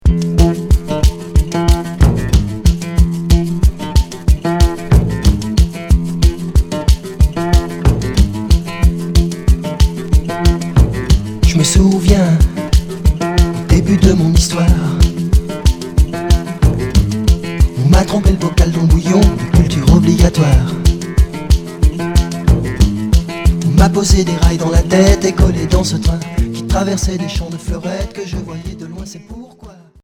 Blues rock Sixième 45t retour à l'accueil